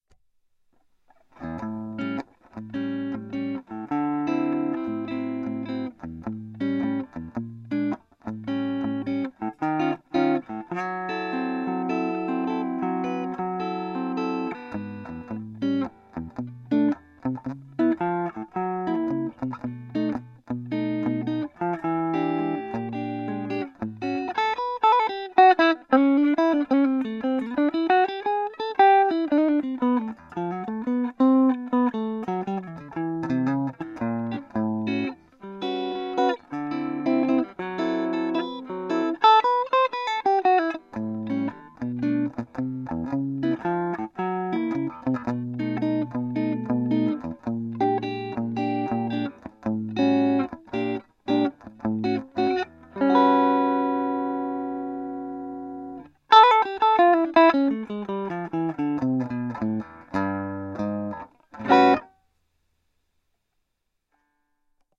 Samples: recorded with DR1 pocket recorder about 2 feet away
Neck pickup,  bass/treble middle setting – no reverb
dry-blues.mp3